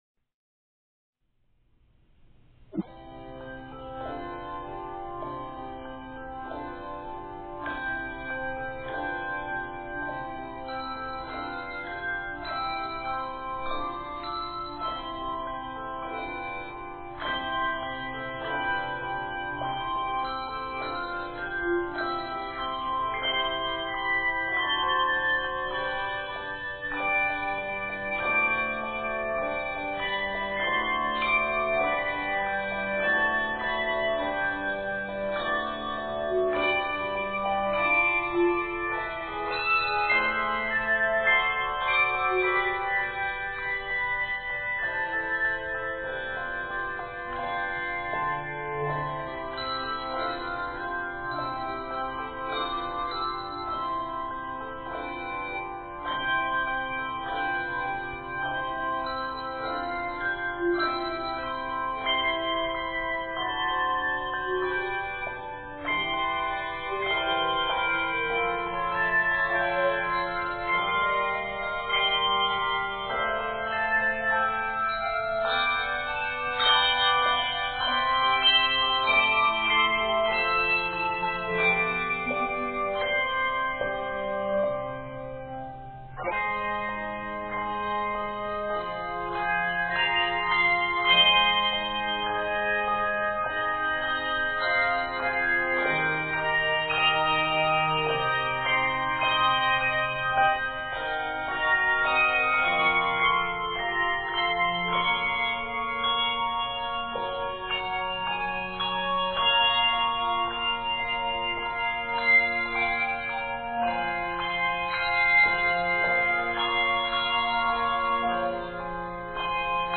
arranged for bells